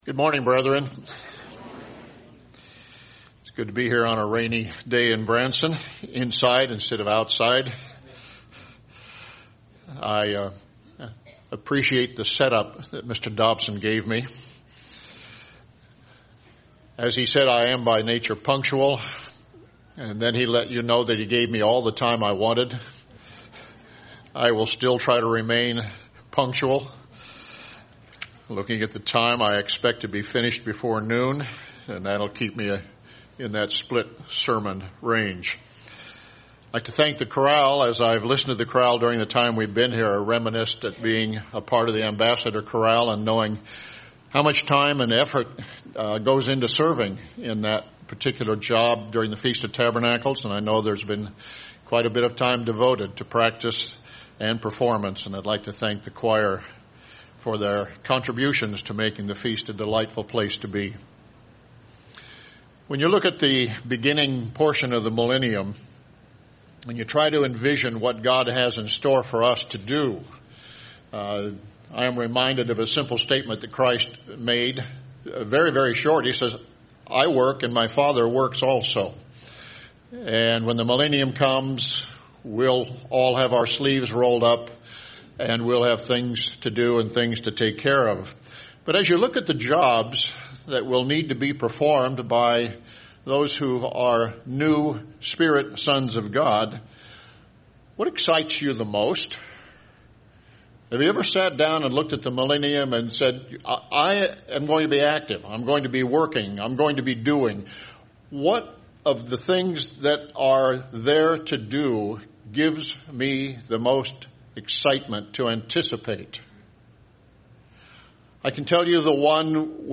This sermon was given at the Branson, Missouri 2023 Feast site.